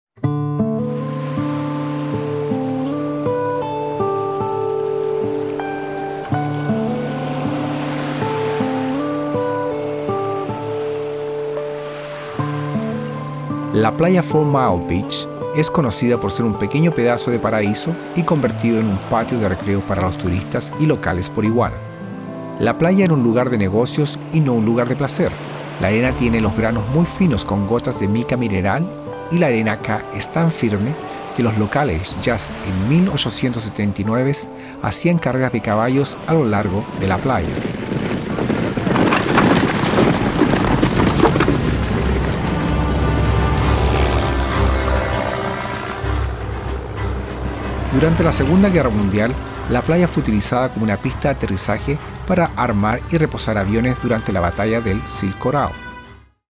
Comentarios activados por GPS:
Cuando vea este símbolo, significa que el tour está operado usando la última tecnología activada por GPS, que ofrece más de 3 horas de comentarios, con efectos de sonido y música, creando una experiencia verdaderamente memorable.
Four-Mile_WEB_Spanish.mp3